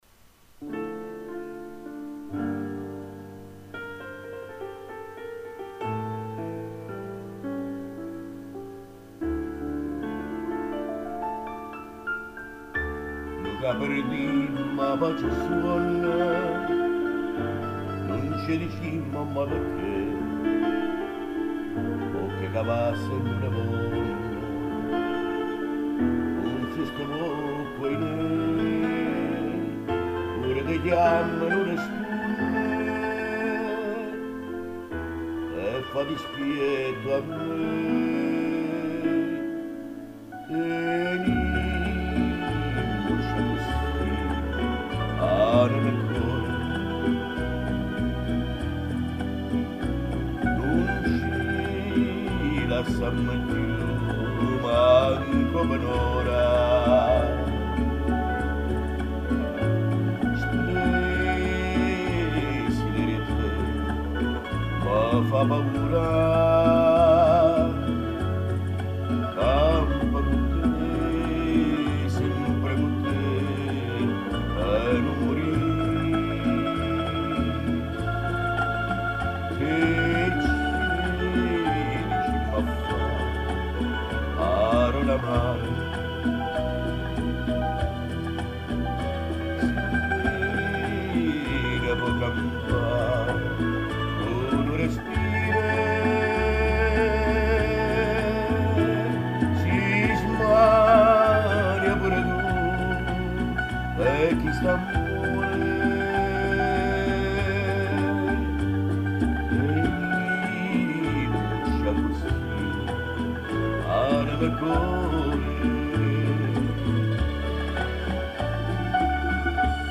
nel suo home studio recorder.